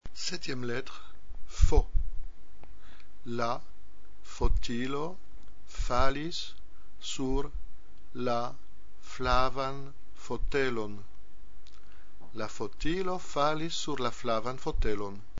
7 - F F
-2) la phrase prononcée lentement en séparant bien les mots,
-3) la phrase prononcée normalement.